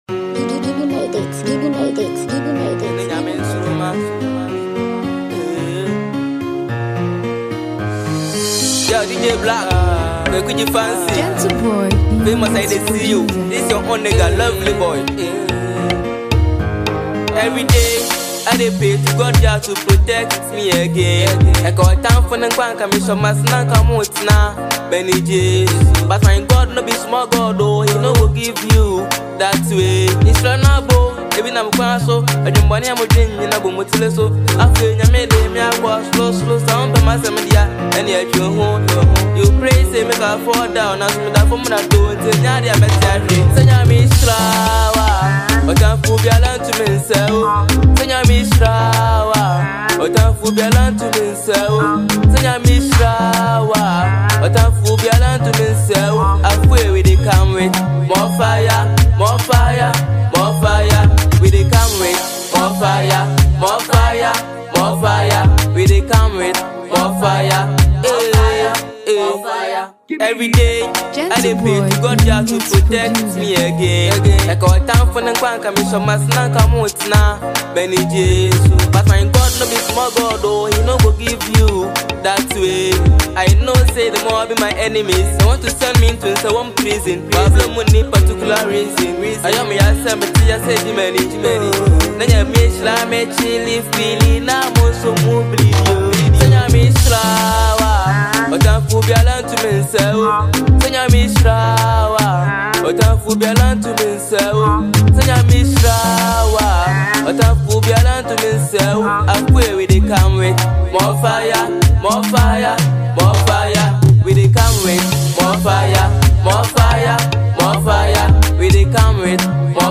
creates a truly uplifting experience.